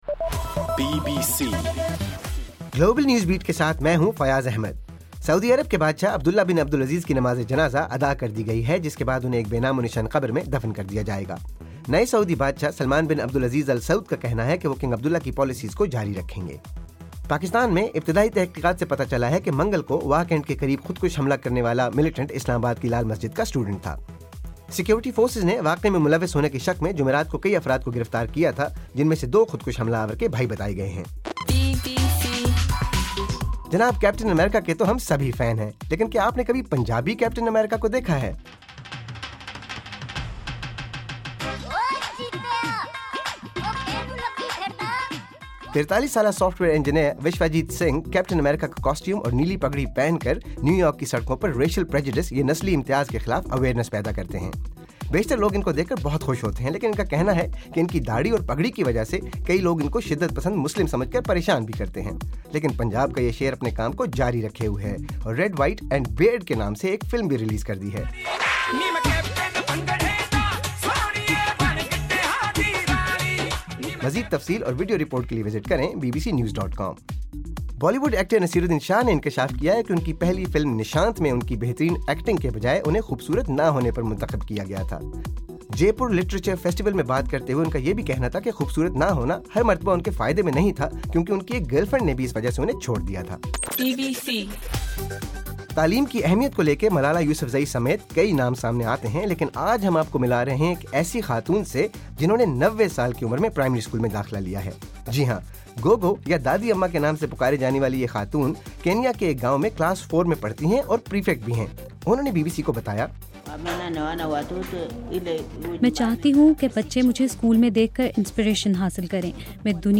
جنوری 23: رات 10 بجے کا گلوبل نیوز بیٹ بُلیٹن